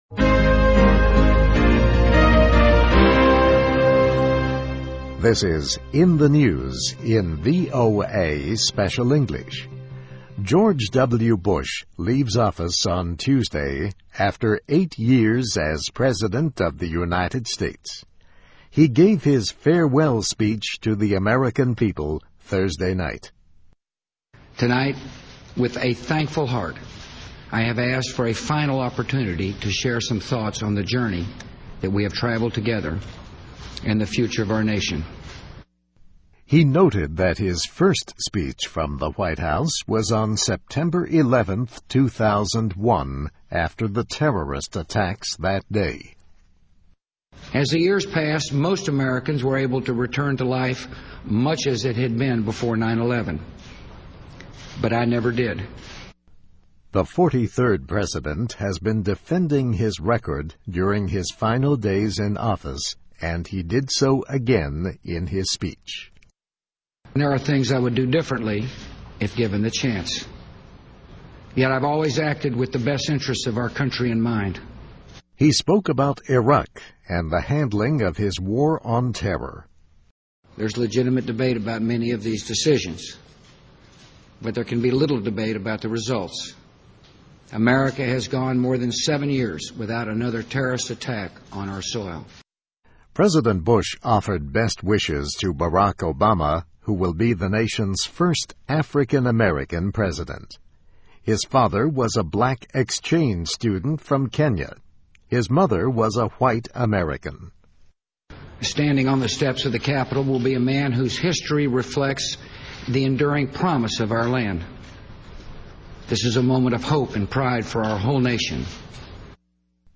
VOA Special English, In the News, Bush Looks Back at Eight Years as President, and Wishes Obama Well